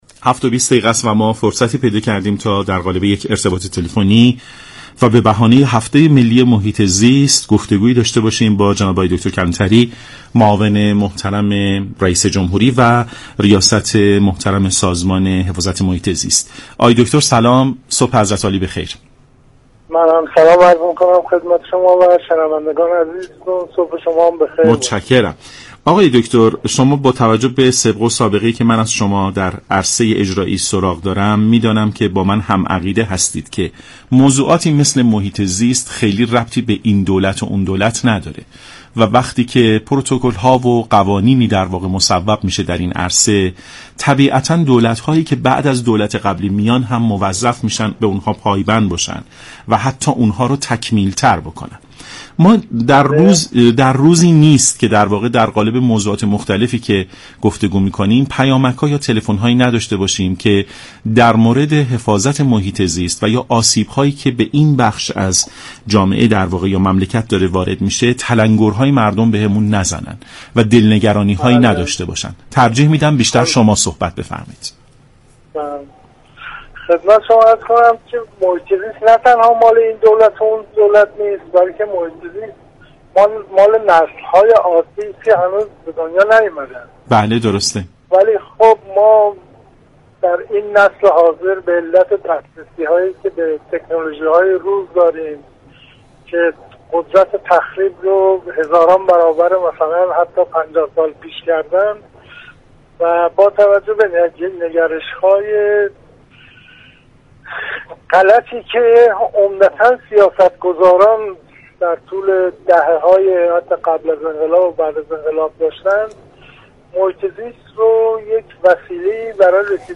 به گزارش شبكه رادیویی ایران، عیسی كلانتری معاون رییس جمهور و رییس سازمان حفاظت از محیط زیست در برنامه سلام صبح بخیر به بحث محیط زیست پرداخت و گفت: محیط زیست و منابع طبیعی مربوط به یك دولت نمی شود و متعلق به همه نسل ها است.